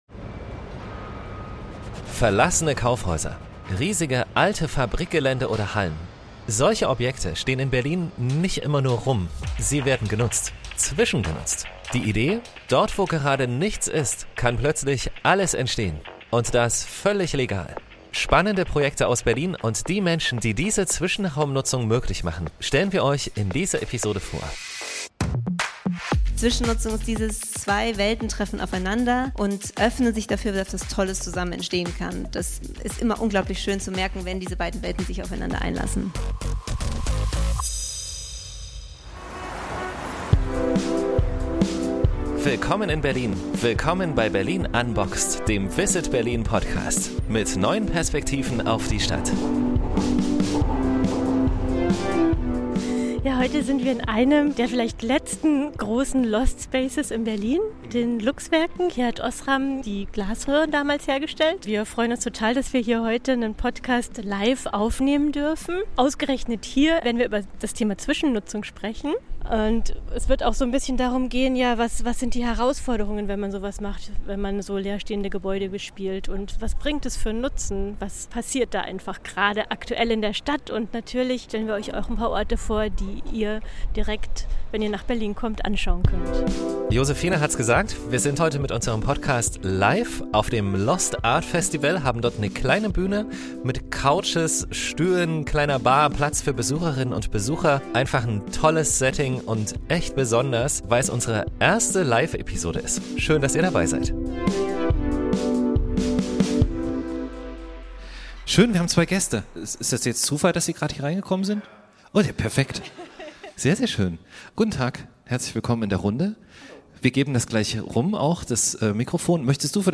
Dazu werfen wir einen Blick hinter die Kulissen aktueller Projekte und diskutieren mit einigen Playern der Berliner Zwischennutzungs-Szene.